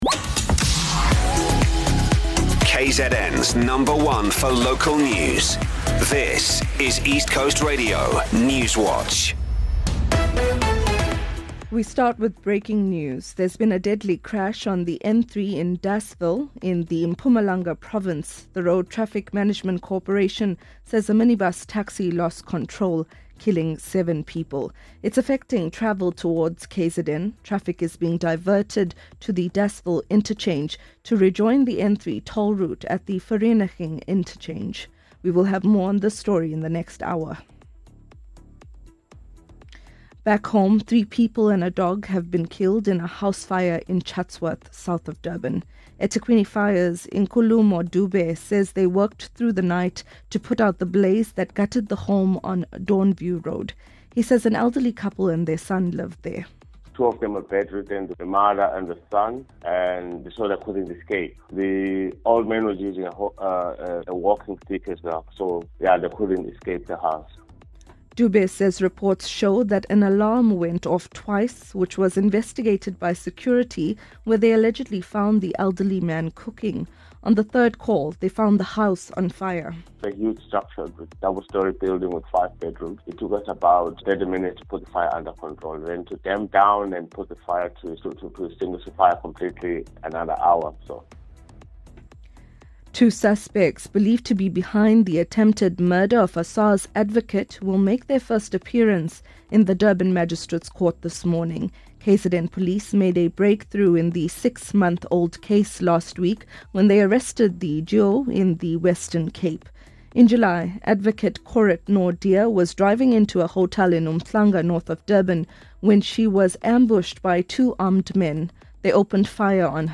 East Coast Radio Newswatch is the independent Durban-based radio station's news team. We are KwaZulu-Natal’s trusted news source with a focus on local, breaking news.